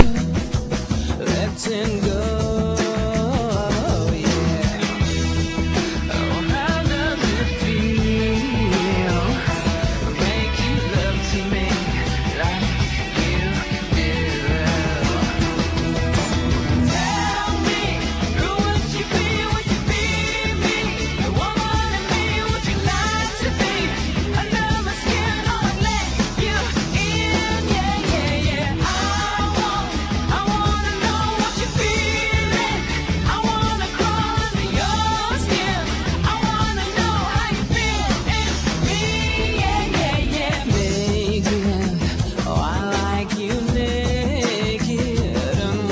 was even a bigger winner and some more rock tinted pop songs